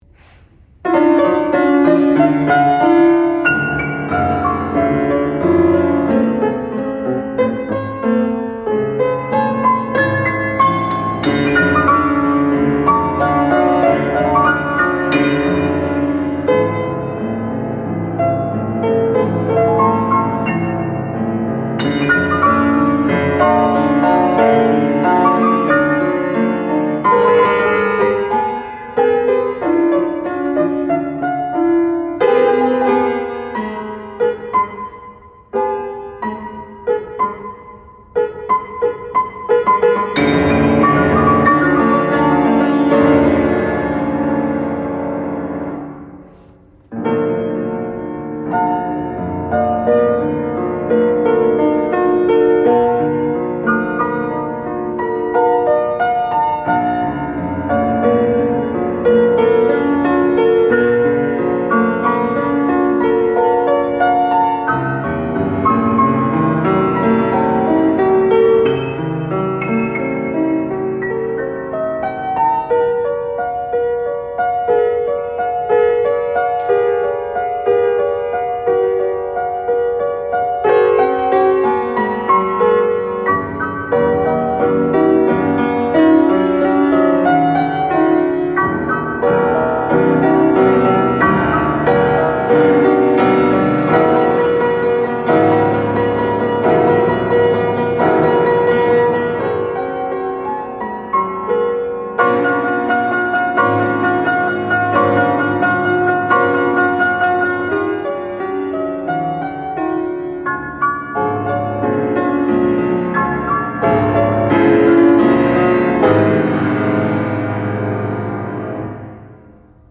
FANTASY PRELUDE for Piano Four-hands: Live Performance
The recording was made at Spelman College on a Steinway piano. The piano sounds a bit brittle, and after a briefly shaky start, the piano deo does an admirable job of tackling this challenging piano duet - the interpretation, the way they feel and play the music, is simply spot-on after that.